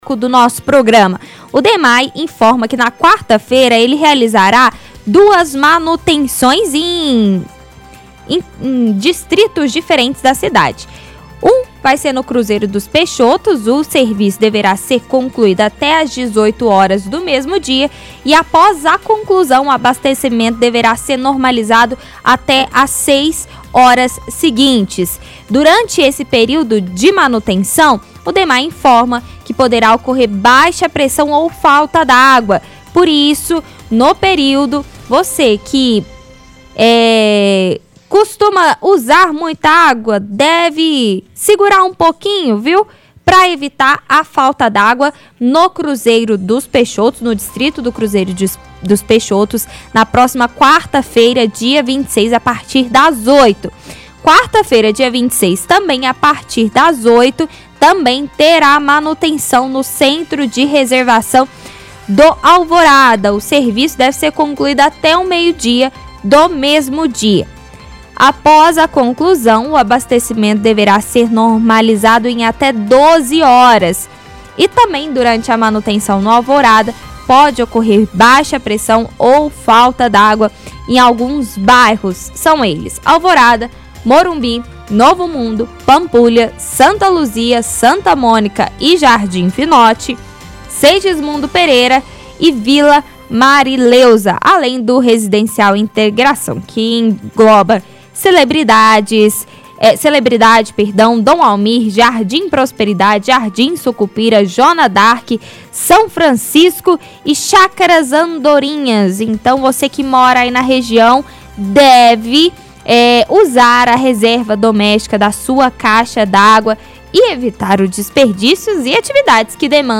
-Apresentadora lê textos do site da Prefeitura.